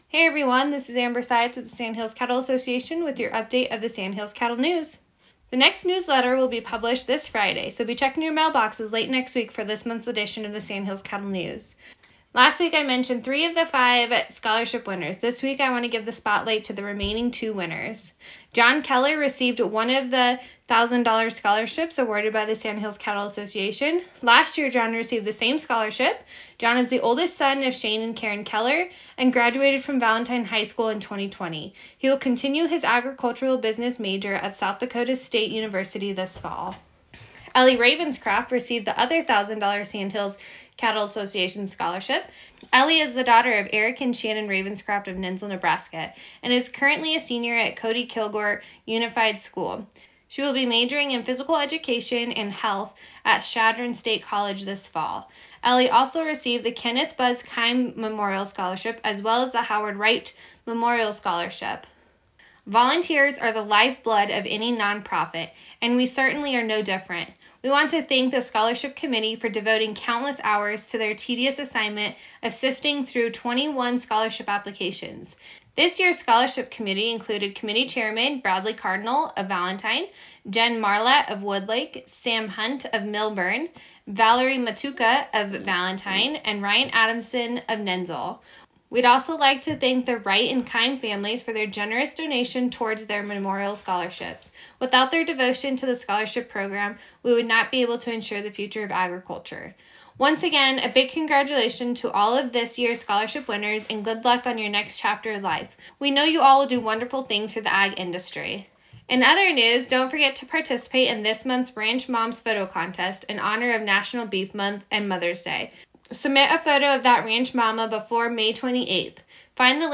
SCA Radio Spot on 5/13/21